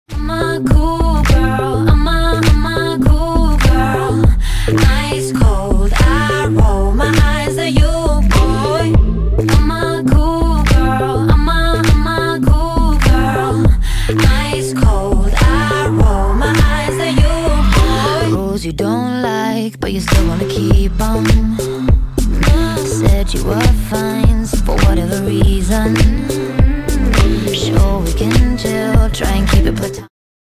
indie pop
alternative